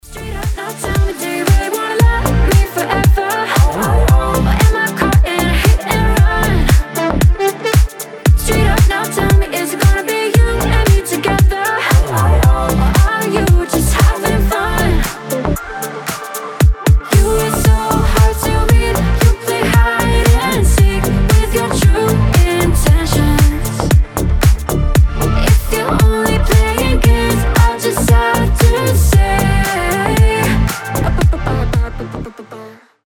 • Качество: 320, Stereo
ритмичные
deep house
заводные
женский голос
Dance Pop